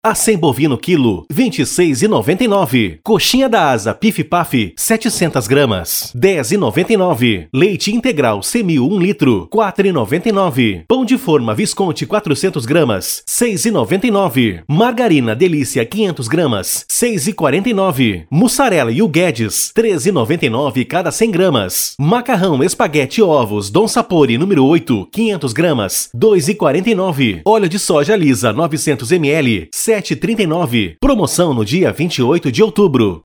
OFF - OFERTAS SUPERMERCADO: